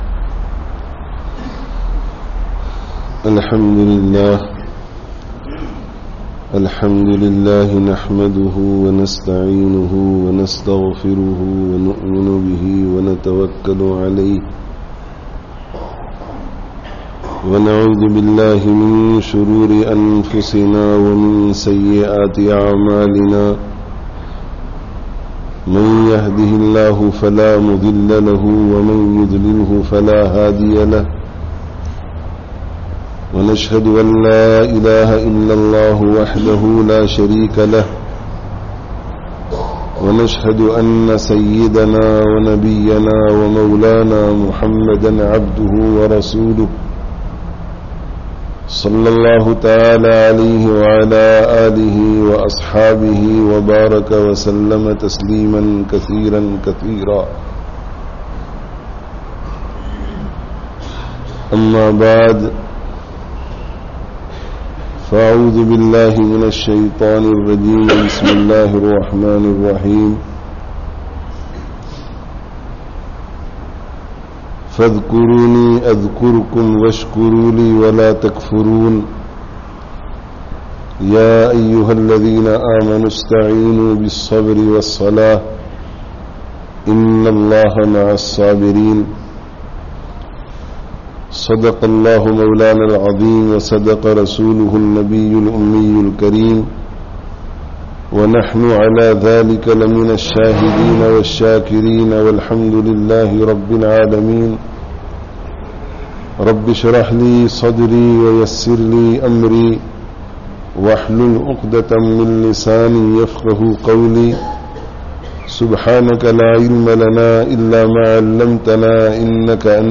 Waqt kī Qadr awr Ākhirat kī Fikr (Masjid-e-Umar, Lusaka, Zambia 24/08/18)